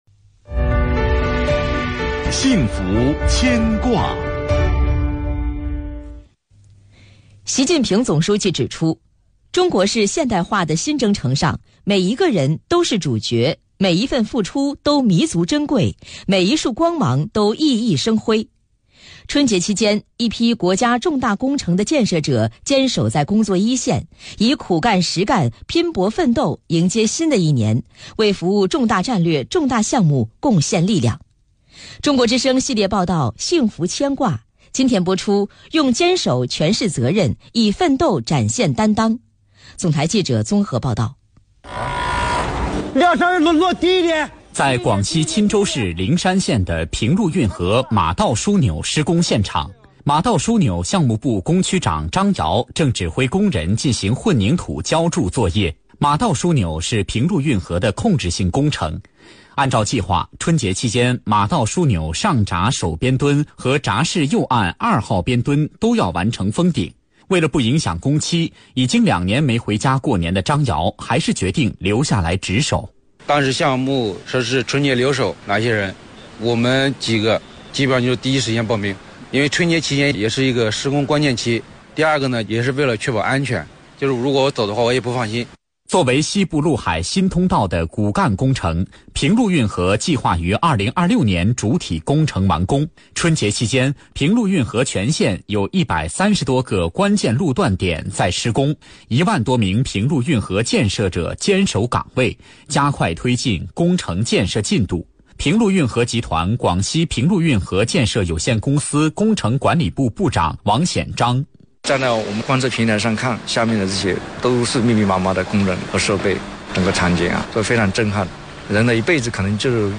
中國之聲系列報道《幸福牽掛》2月2日推出《用堅守詮釋責(zé)任 以奮斗展現(xiàn)擔(dān)當(dāng)》。